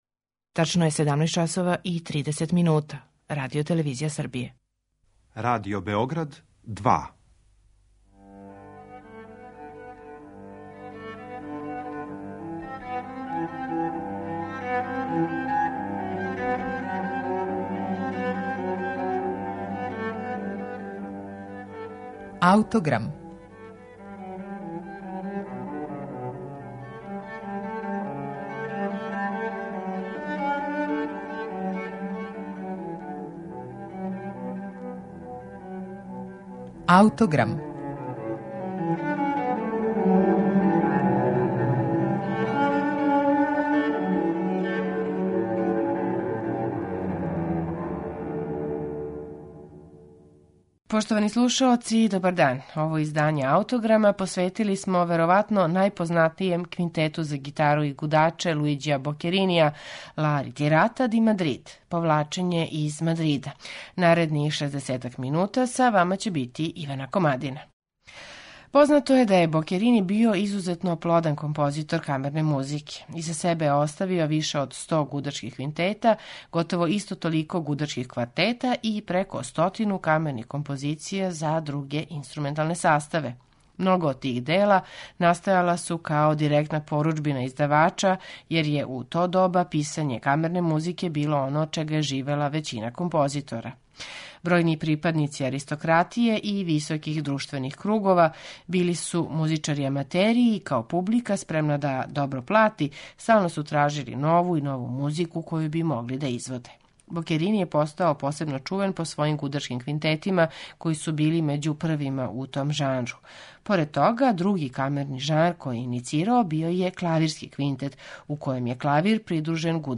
Овај Бокеринијев квинтет слушаћете у интерпретацији гитаристе Пепеа Ромера и камерног ансамбла Академије St Martin in the Fields .